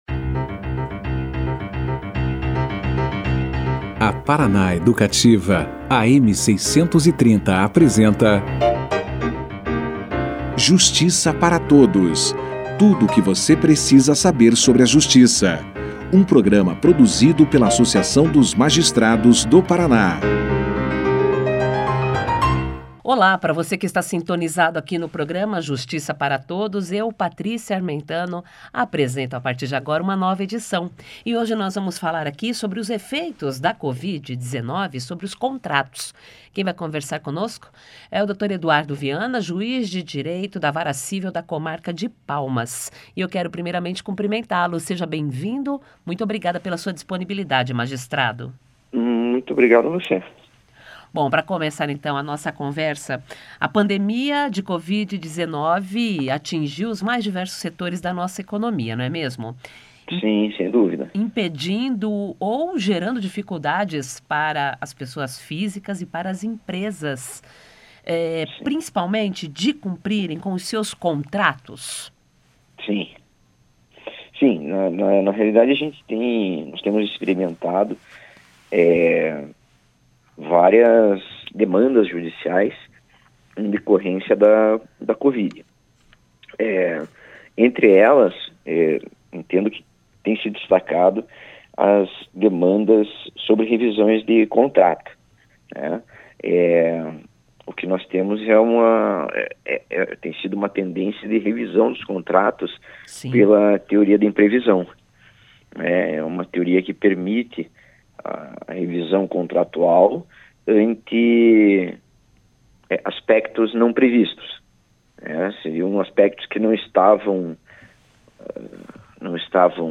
Diante dessa situação, quais são as soluções jurídicas aplicáveis? O Programa Justiça Para Todos ouviu o Juiz de Direito com atuação na Vara Cível da Comarca de Palmas, Eduardo Vianna, sobre o assunto. Segundo ele, a revisão contratual é um direito das partes e uma das alternativas em um momento de crise como este. Confira aqui a entrevista na integra.